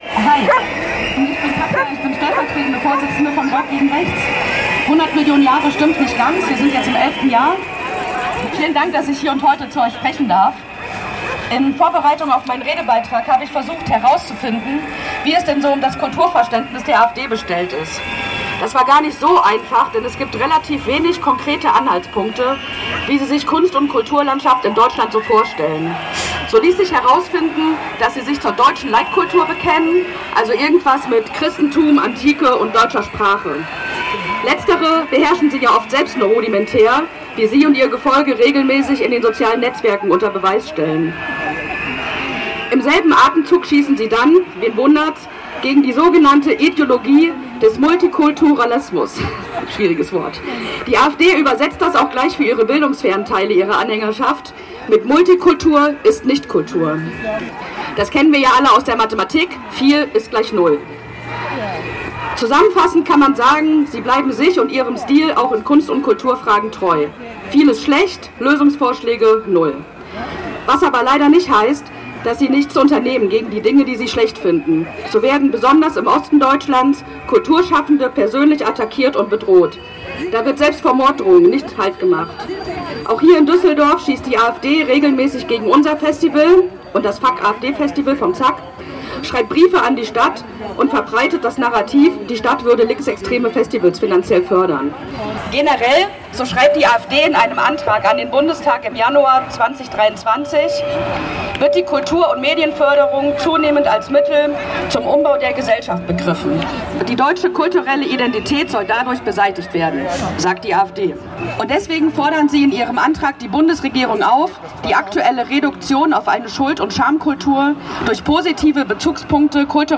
Kundgebung „Düsseldorf stellt sich quer gegen extreme Rechte und Rassismus!“ (Audio 6/7)